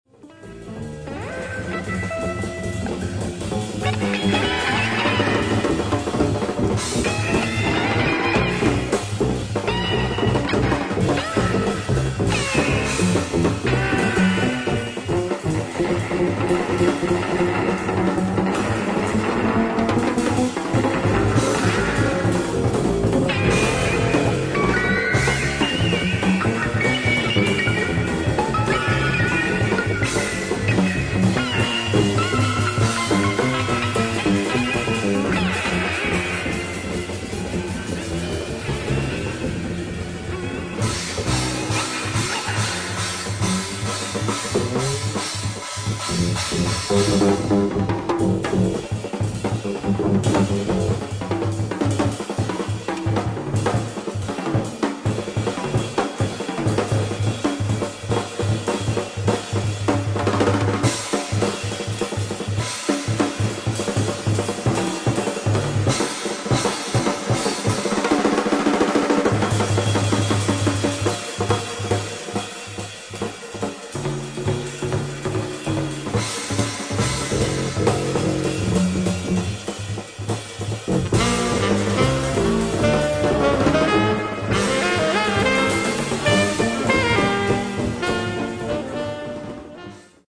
フリージャズ〜ファンク色のある作品まで熱い演奏が繰り広げられています。